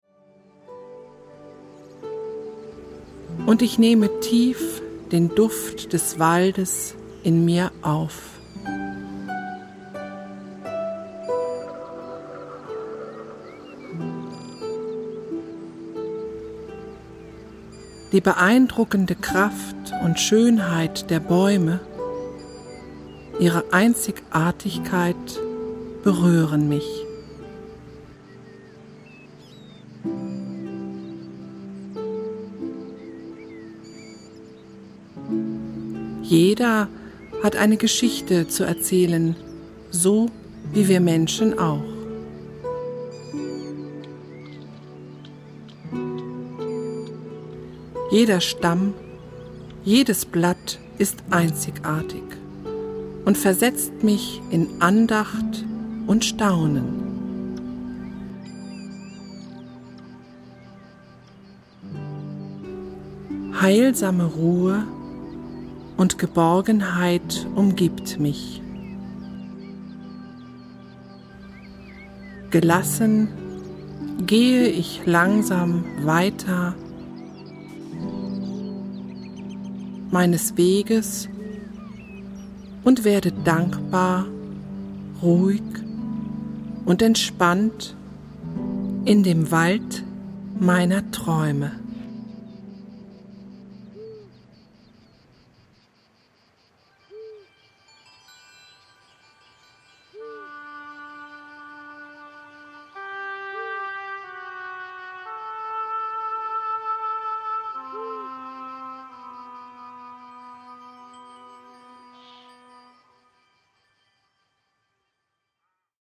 Eine innere Reise zur Ruhe mit entspannenden und bewußtseinserweiternden Texten, untermalt mit Naturgeräuschen und weichsanften Klängen.